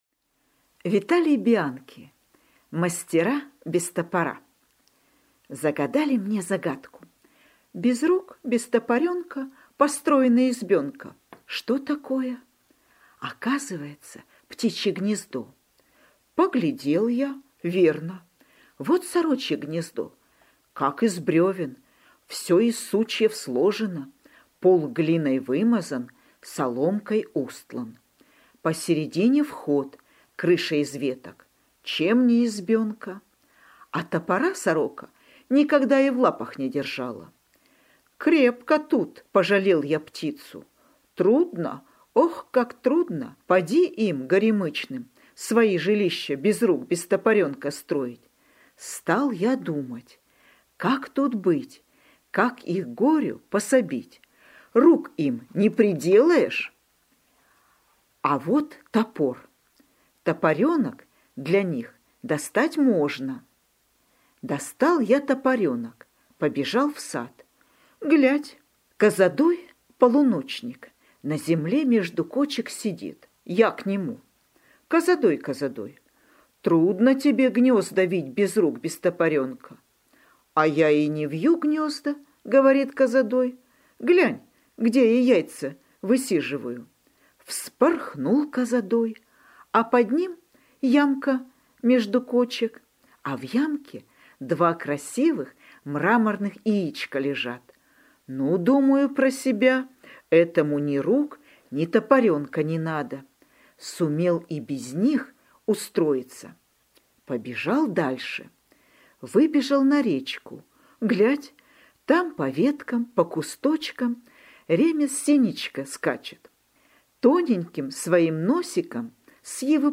Аудиорассказ «Мастера без топора»